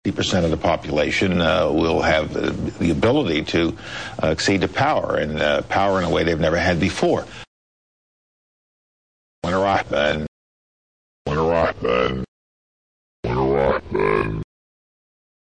また別のおりに、パウエル国務長官がイラクの脅威に関して語った際、
(mp3) というリバース・スピーチも見られた。